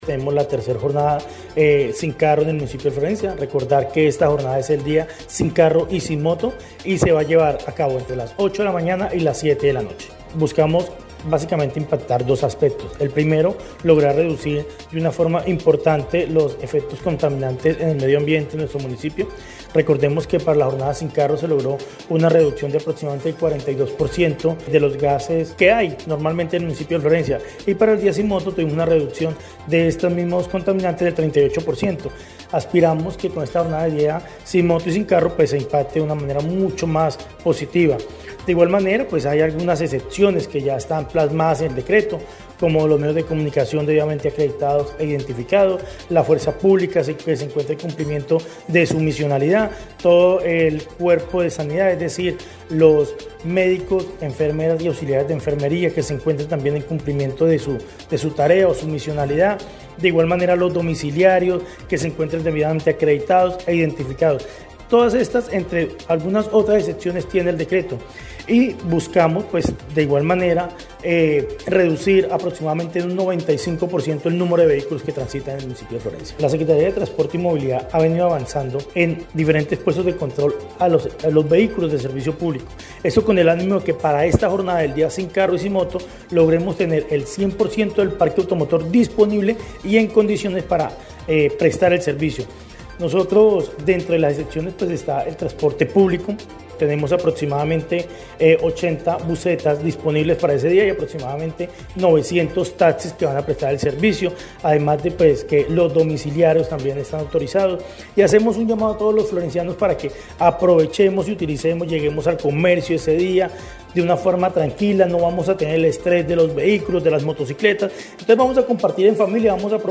De acuerdo con Duberney Gutiérrez Artunduaga, secretario de transporte y movilidad en la ciudad de Florencia, dijo que para ese día se espera disminuir los niveles de contaminación por CO2 por culpa de la movilidad de vehículos y motocicleta.